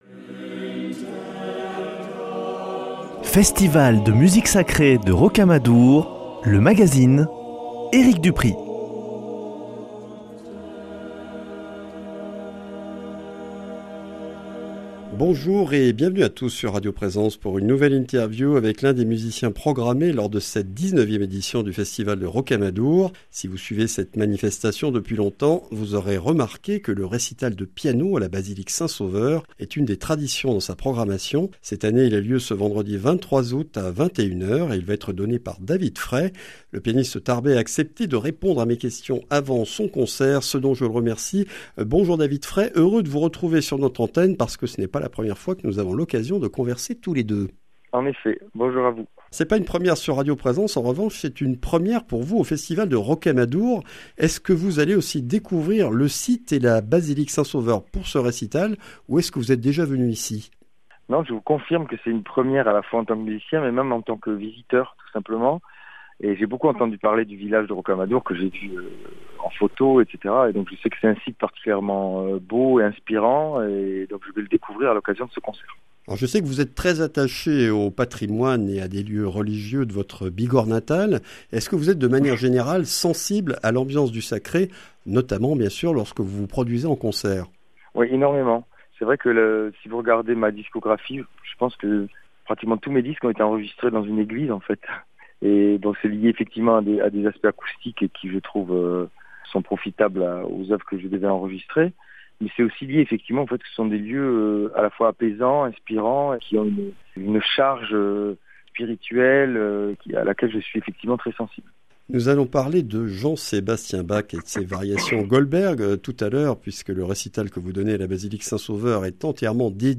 Dans cet entretien, il revient sur sa rencontre et sa relation avec son maître, Jacques Rouvier, sur sa passion pour Bach et sur le projet de L’Offrande Musicale, festival qu’il a fondé et dirige depuis trois ans.